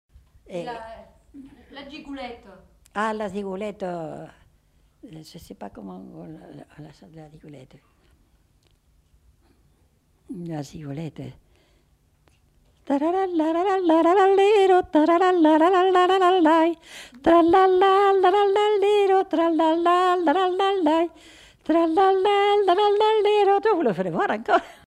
Aire culturelle : Haut-Agenais
Lieu : Condezaygues
Genre : chant
Effectif : 1
Type de voix : voix de femme
Production du son : fredonné
Danse : gigue